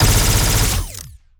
Plasmid Machinegun B
GUNAuto_Plasmid Machinegun B Burst_02_SFRMS_SCIWPNS.wav